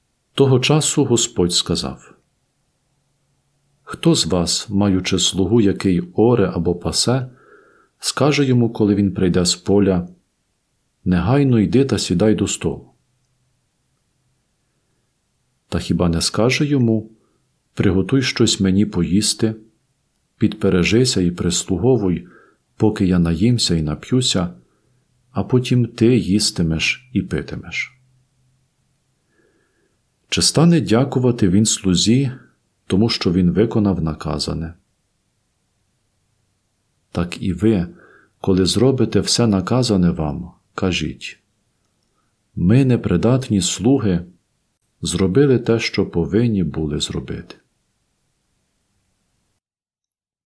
Євангеліє